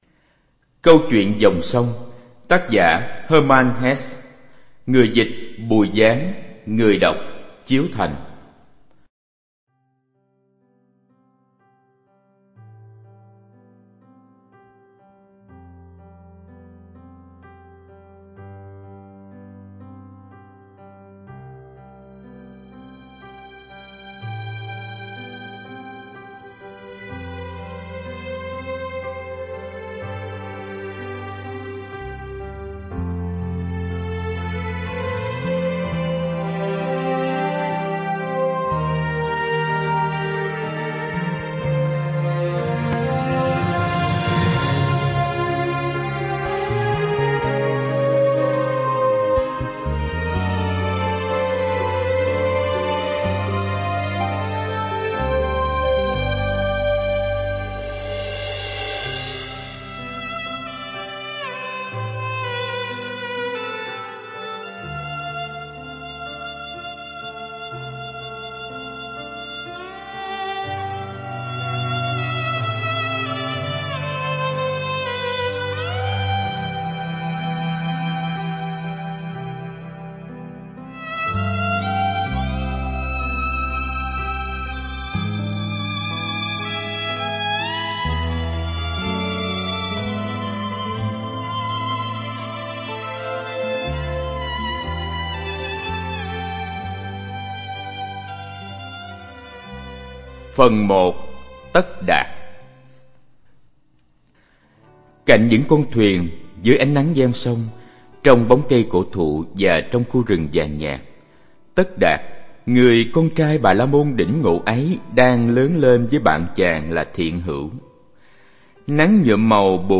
(Audio book) Câu chuyện dòng sông